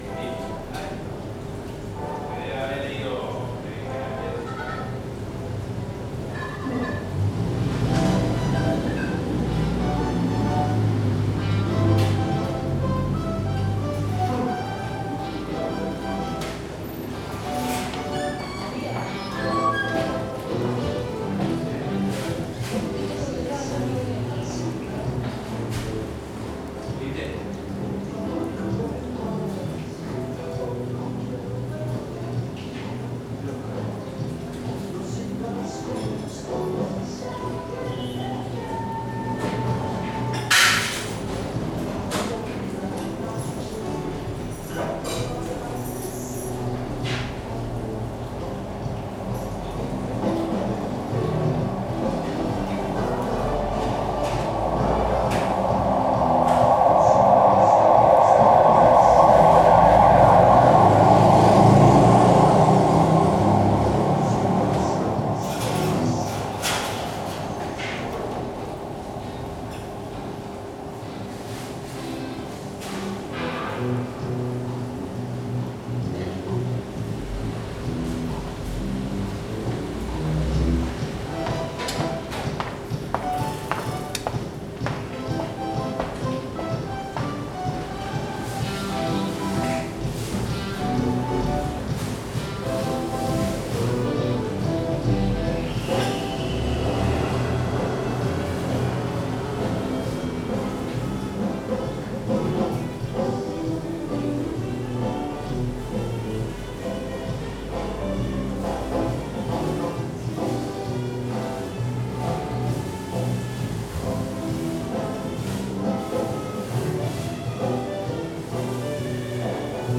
psr-bar-la-muestra.mp3